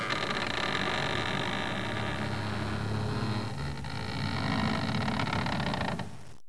opendoor.wav